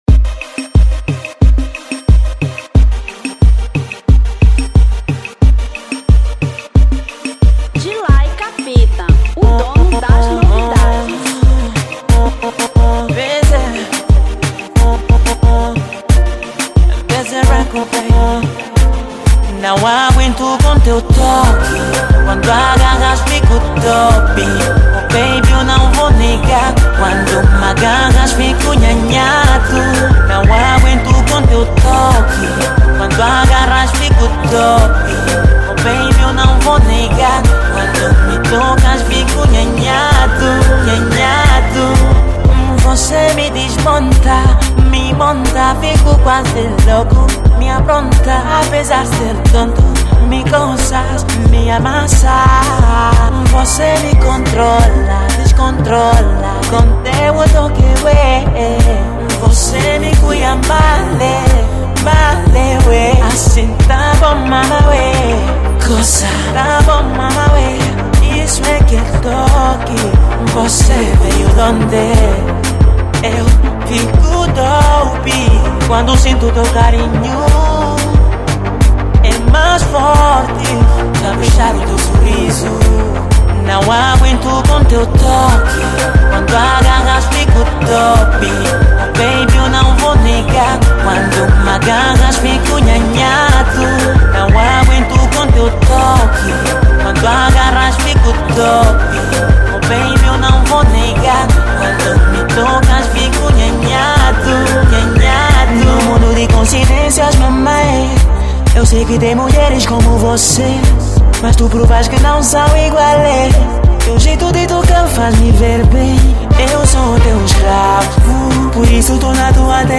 Zouk 2016